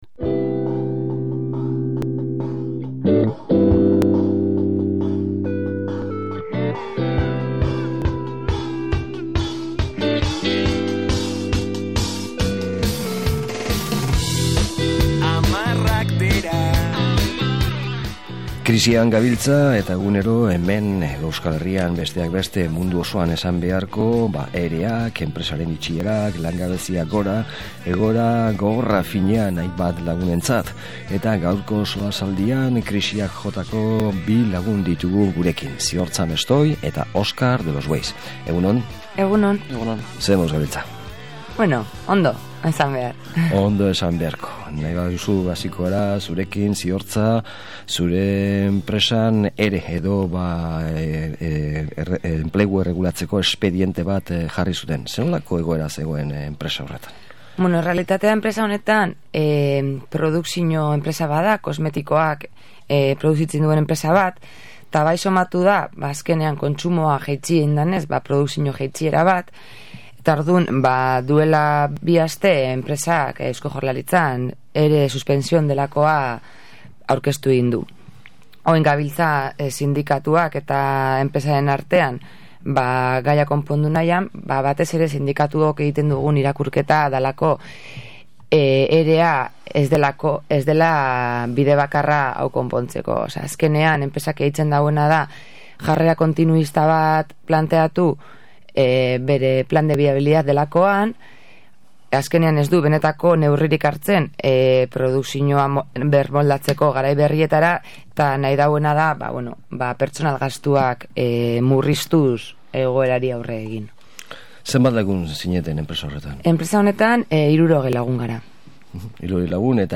SOLASALDIA : Enplegua Erregulatzeko Txostenak
solasaldia